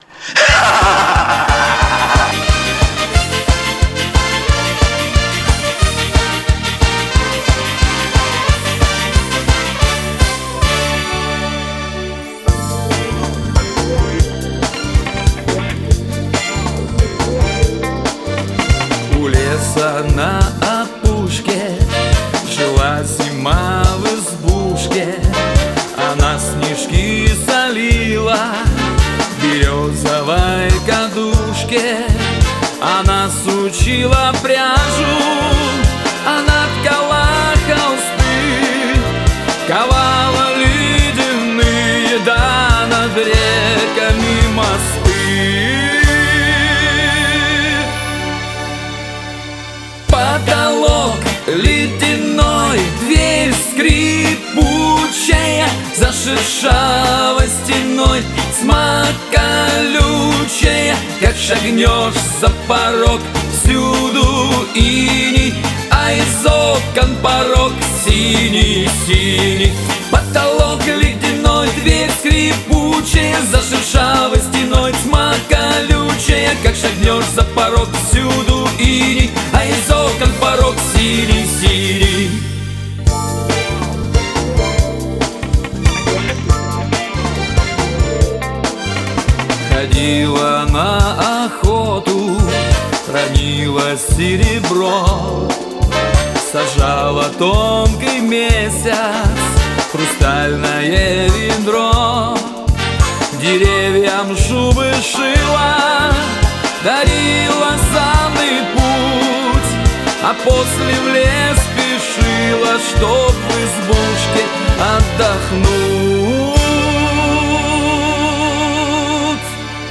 вокал, гитара
бэк вокал
альт-саксофон, клавишные
ударные, перкуссия